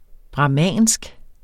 Udtale [ bʁɑˈmæˀnsg ]